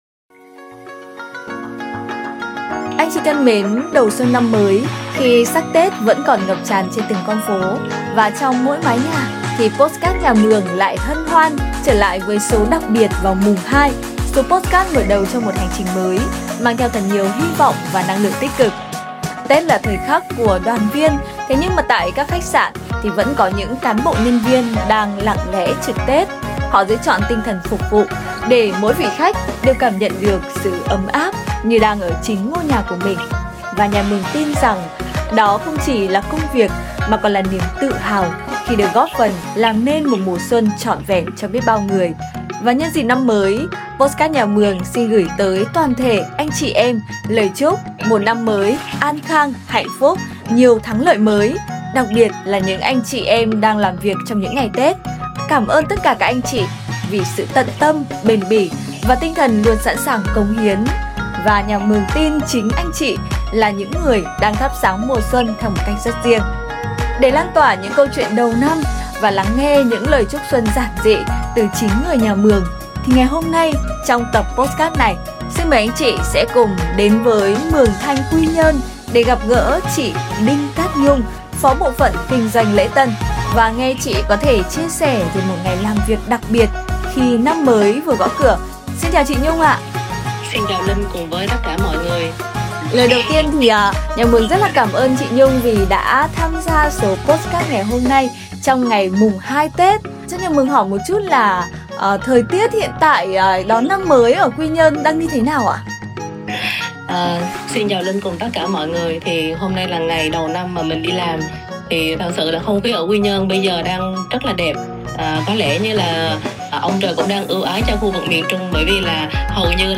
Tập podcast đầu tiên của năm mới là một cuộc trò chuyện